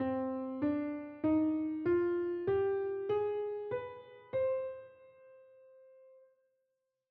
The harmonic minor scale has all the same notes as the natural minor EXCEPT the 7th.
Figure 5.2 C harmonic minor.
C-Harmonic-Minor-Scale-S1.wav